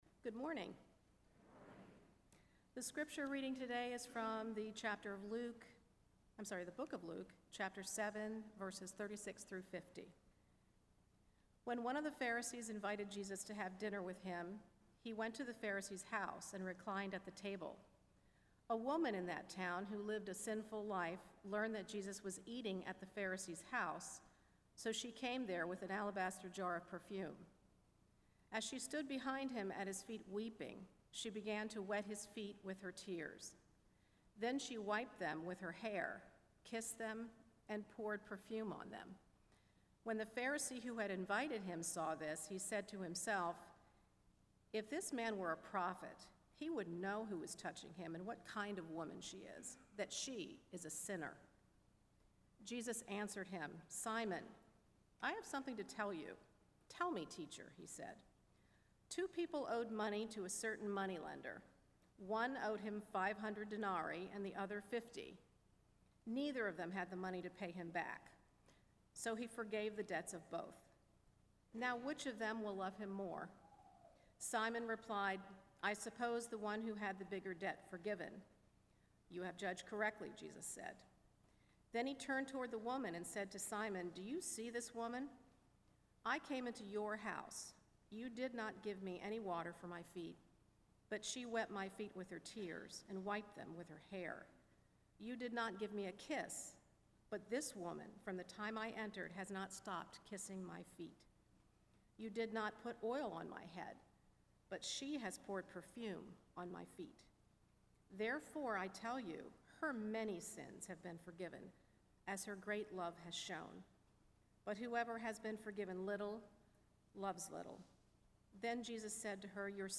RSS Standalone Sermon